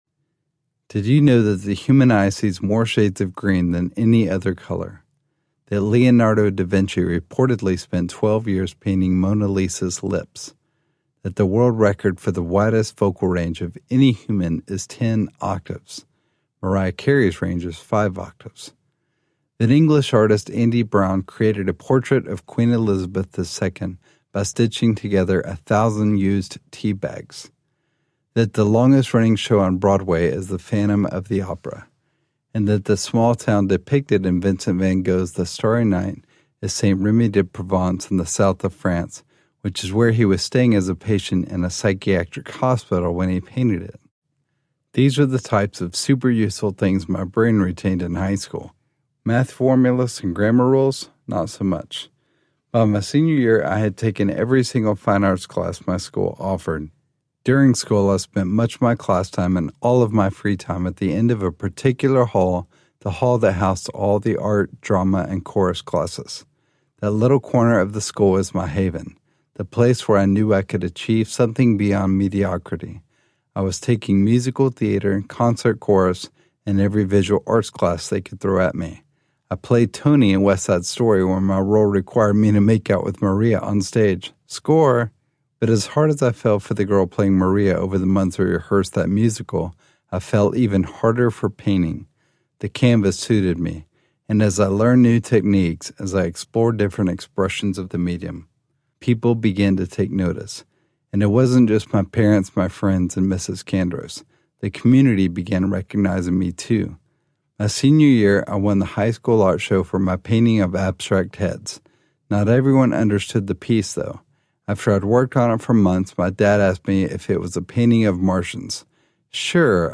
I’m Possible Audiobook
Narrator